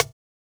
percussion04.wav